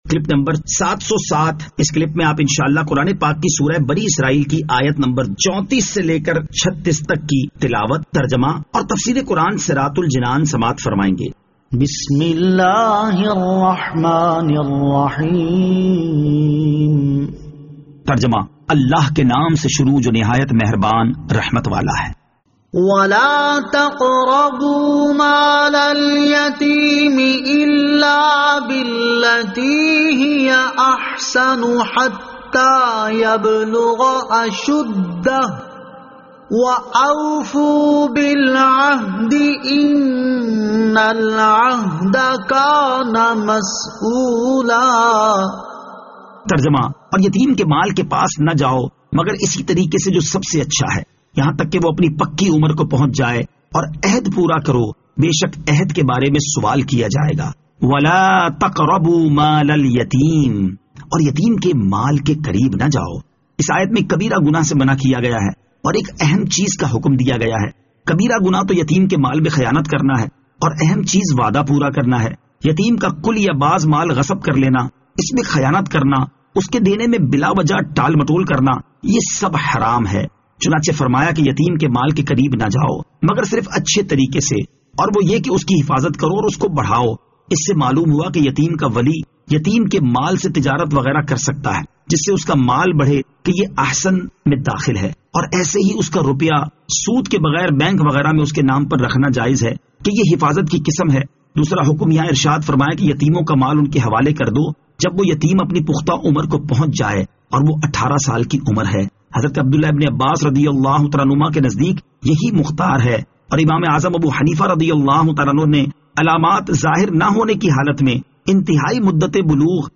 Surah Al-Isra Ayat 34 To 36 Tilawat , Tarjama , Tafseer